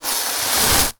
pgs/Assets/Audio/Magic_Spells/fireball_conjure_01.wav at master
fireball_conjure_01.wav